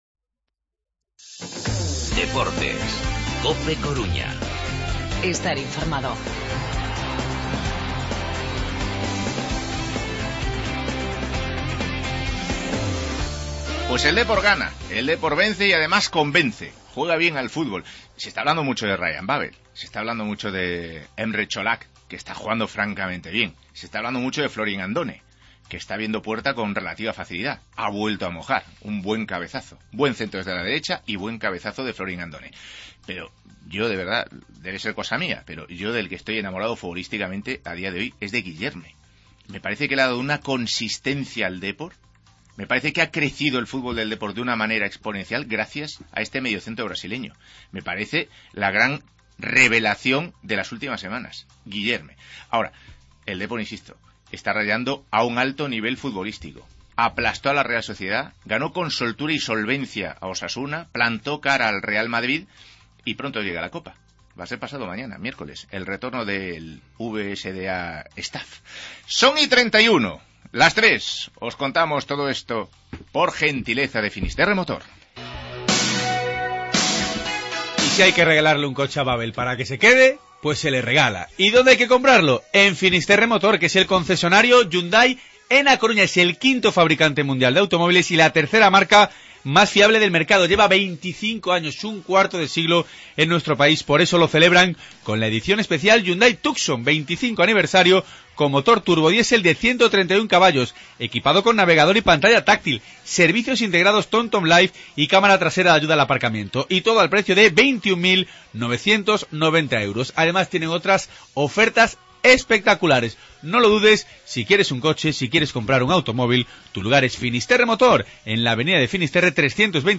AUDIO: Los sonidos del Deportivo-Osasuna, la continuidad de Babel y el análisis de Paco Liaño.